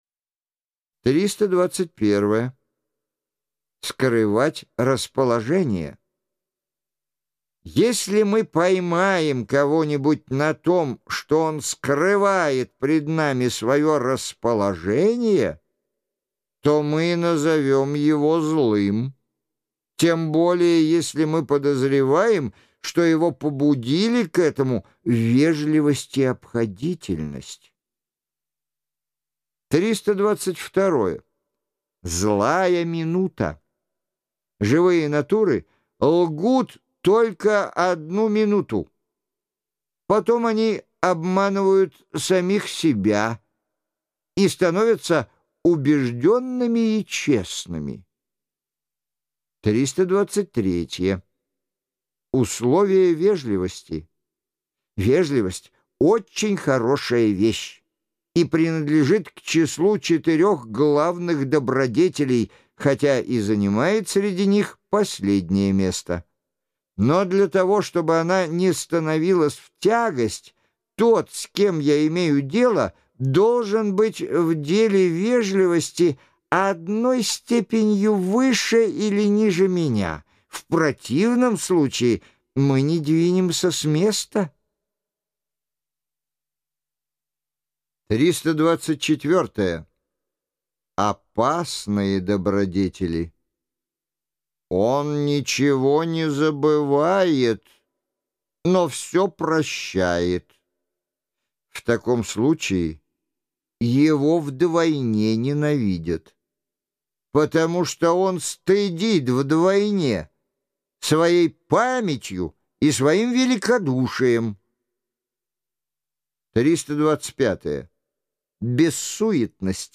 Жанр: Аудиокнига.